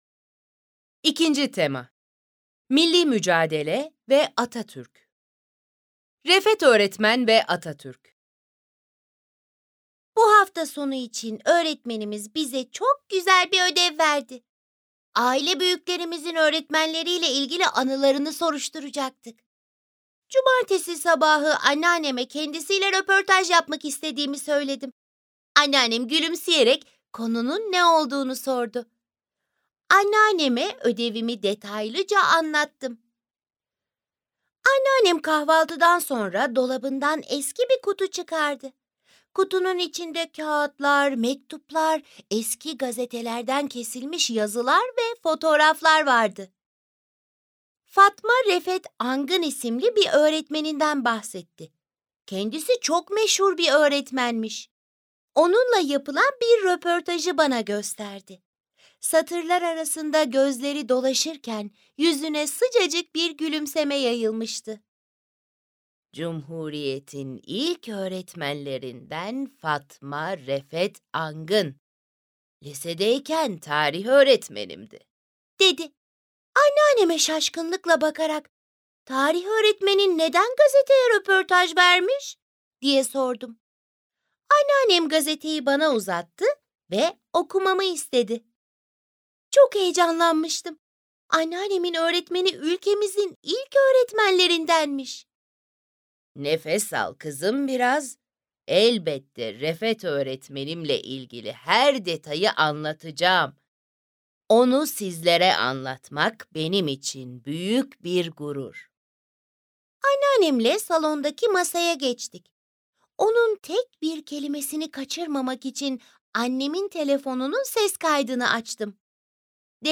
Kategori 3.Sınıf Türkçe Dinleme Metinleri